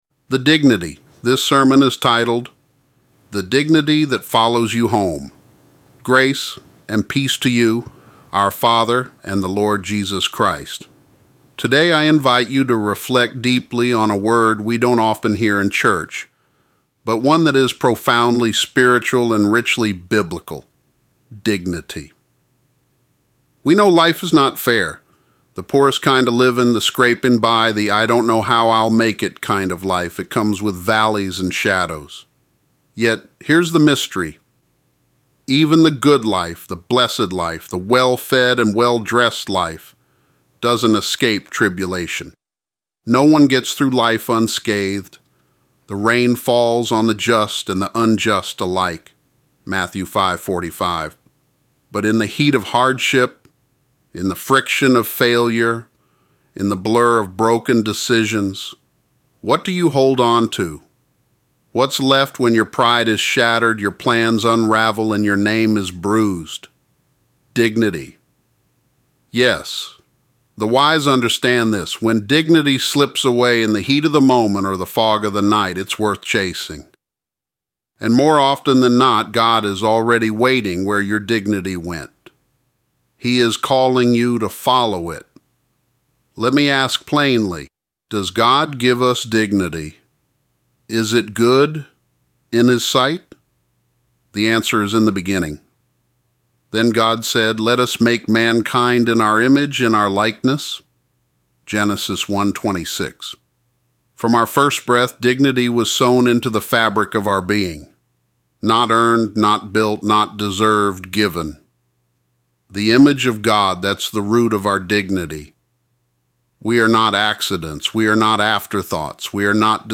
This Sermon is titled: “The Dignity That Follows You Home”